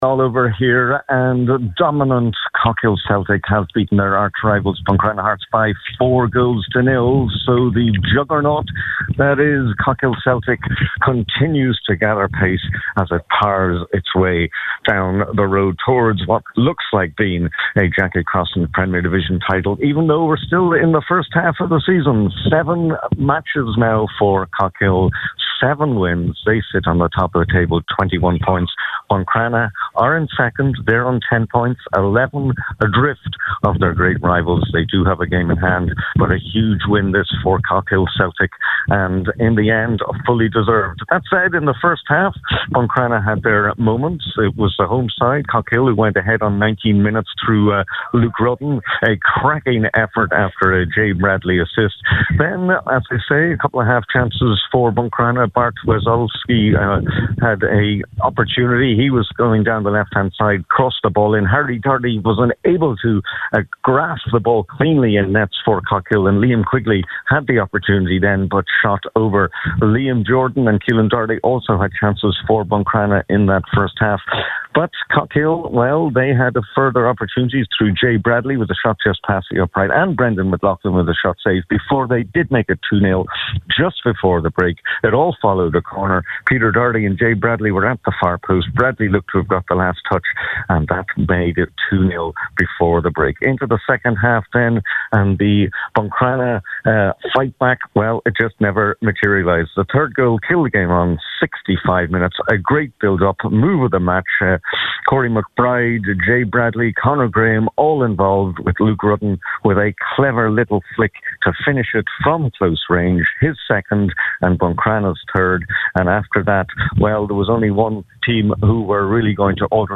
live at full time for Highland Radio Sport…